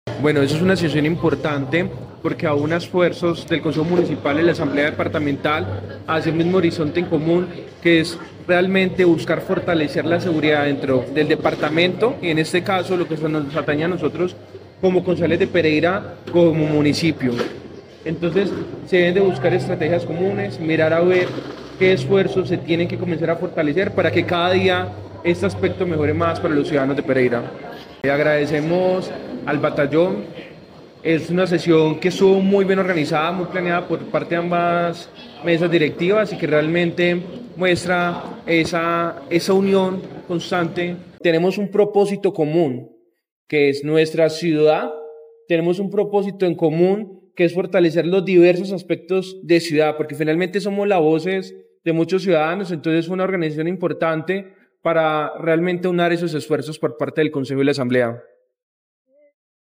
En las instalaciones del Batallón de Artillería de Campaña No. 8 San Mateo se llevó a cabo una sesión conjunta y descentralizada entre la Asamblea Departamental de Risaralda y el Concejo de Pereira, un espacio institucional clave para analizar la situación de seguridad del departamento y avanzar en acciones articuladas frente a la alerta temprana 001 de 2026.
Escuchar Audio Sebastián Ciro, Concejal de Pereira